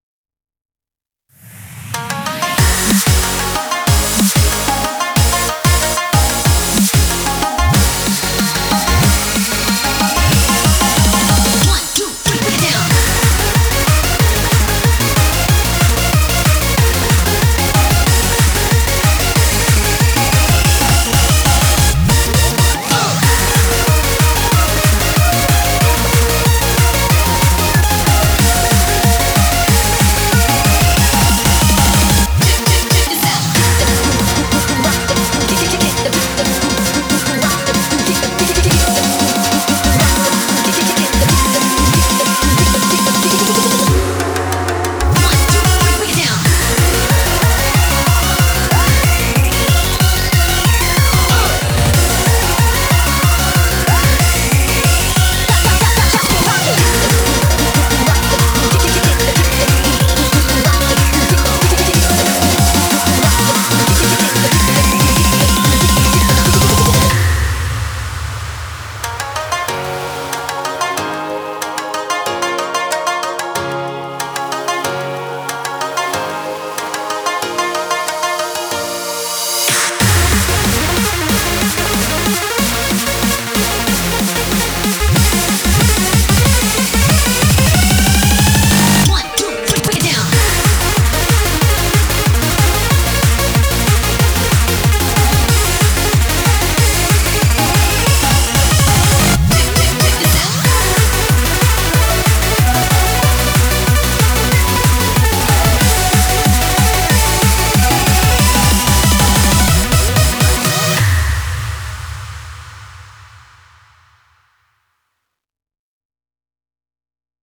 BPM186
Audio QualityPerfect (Low Quality)